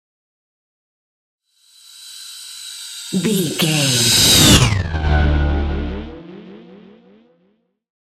Sci fi ship vehicle pass by
Sound Effects
futuristic
pass by